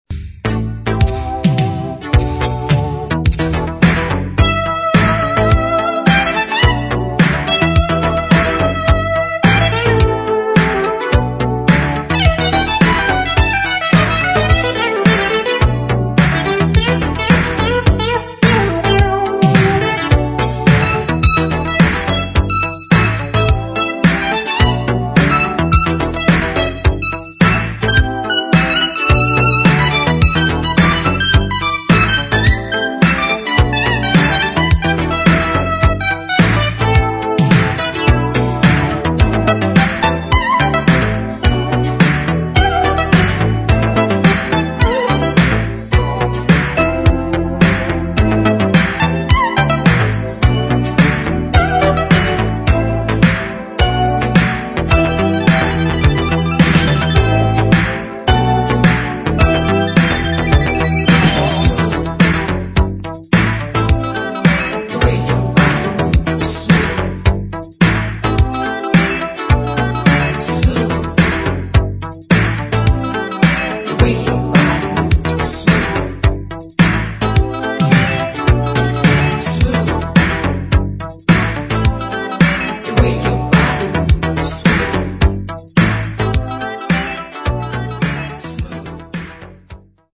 [ DISCO ]